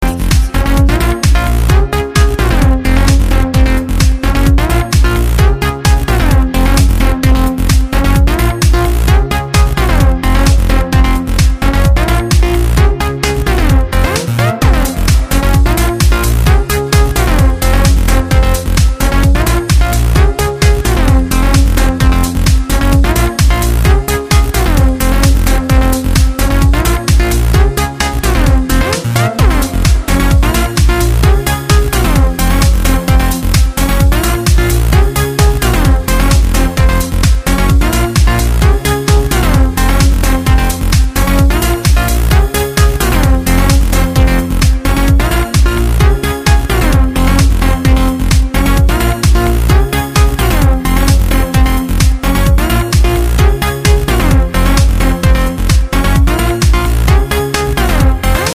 Танцевальные [47]